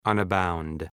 Προφορά
{ʌn’baʋnd}